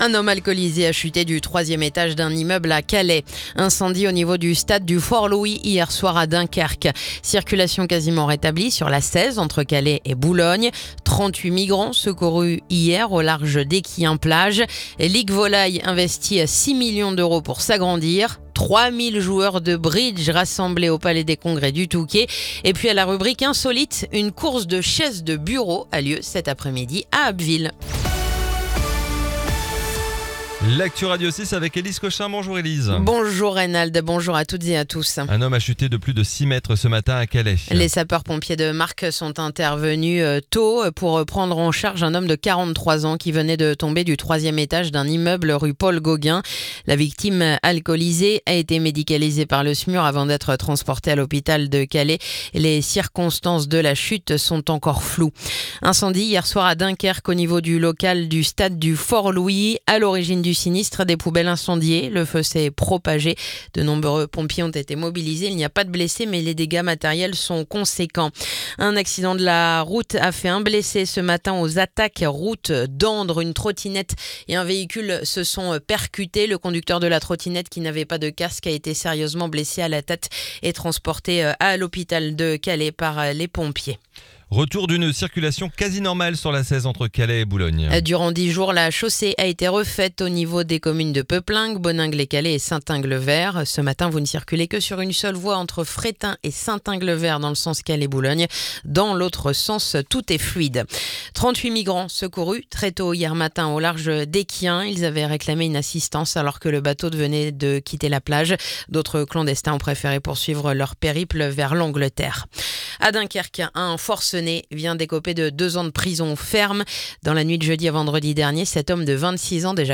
Le journal du mercredi 27 août